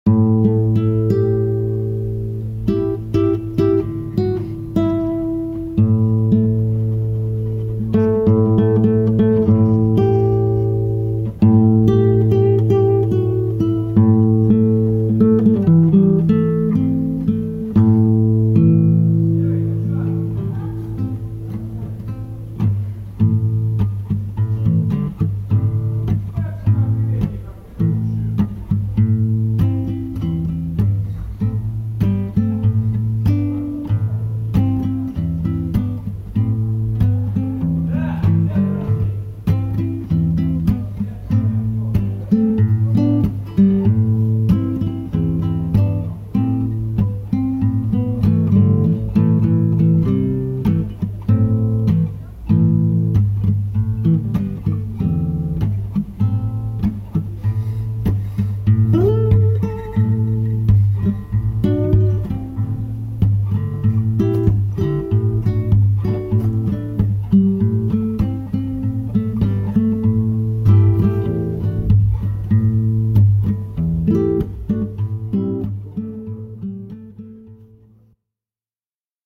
все в основном записано на диктофон, гитары разные, местА шумные и как обычно не вполне способствующие...